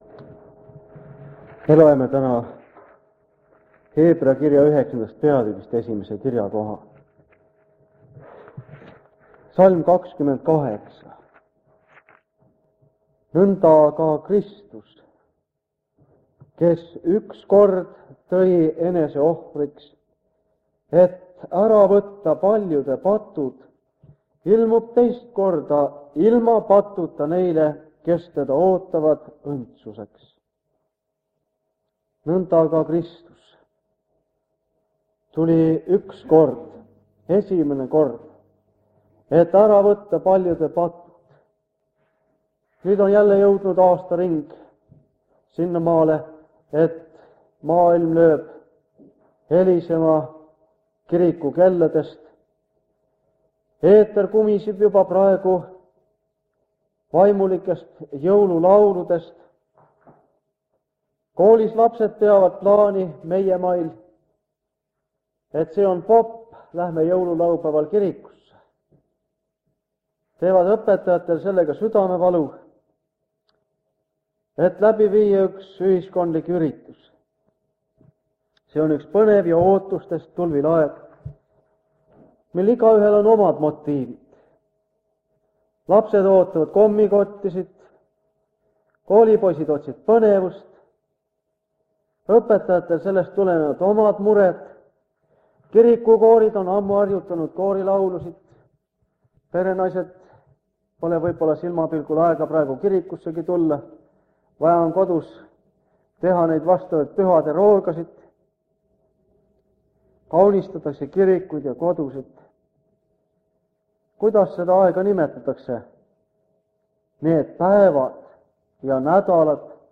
Jutlus vanalt lintmaki lindilt 1979 aasta advendiajast.